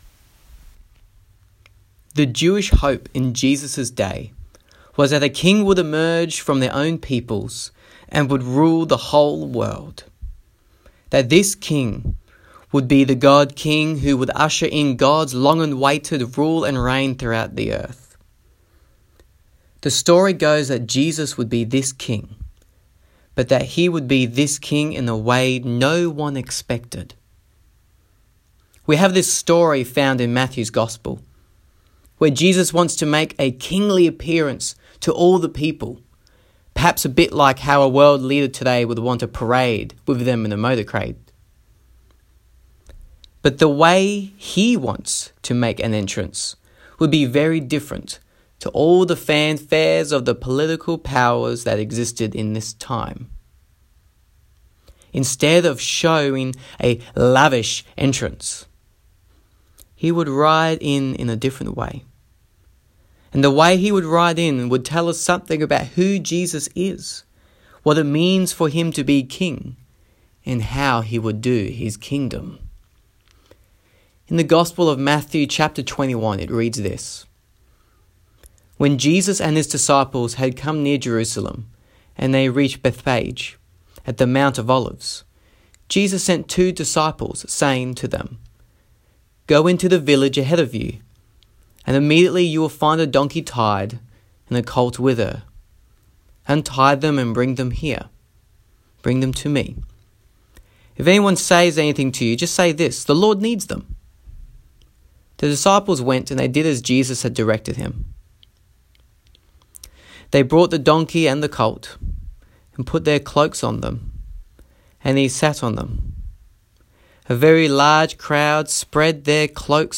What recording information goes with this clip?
Note: Audio quality is not great due to not using my podcast mic